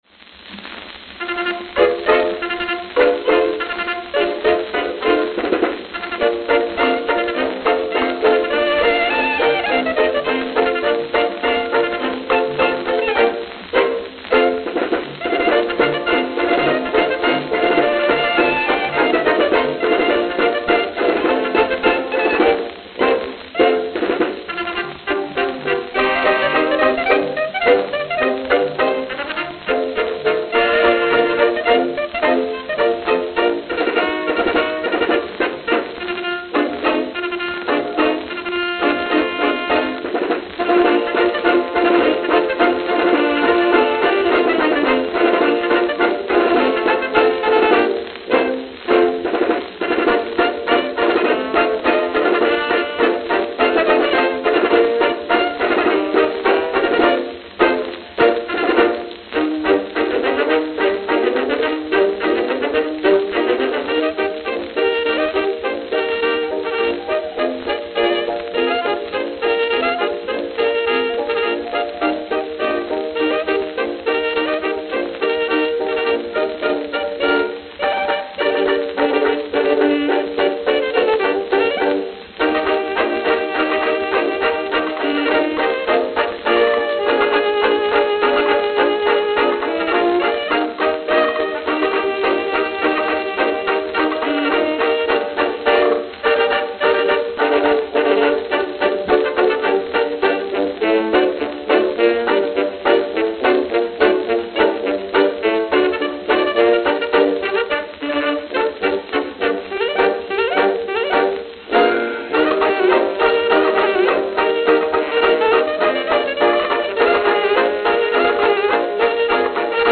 Note: Very worn. Rumble filtered.